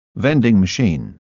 3-vendingMachine
3-vendingMachine.mp3